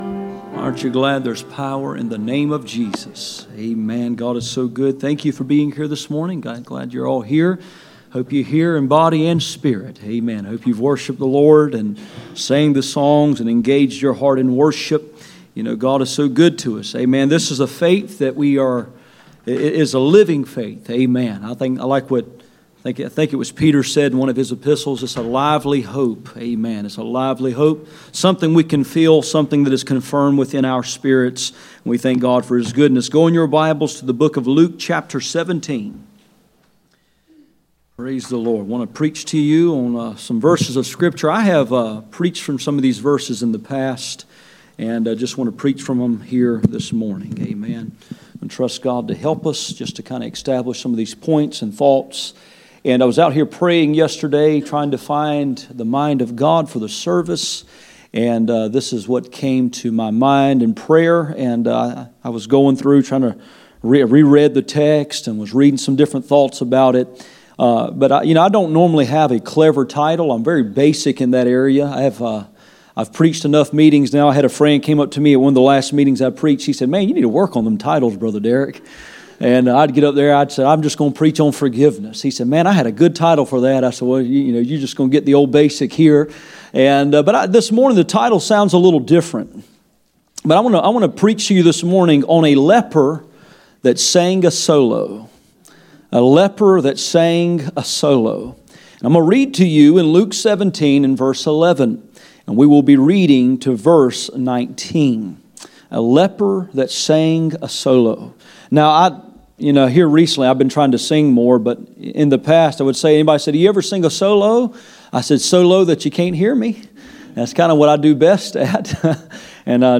None Passage: Luke 17:11-19 Service Type: Sunday Morning %todo_render% « Stay in Crete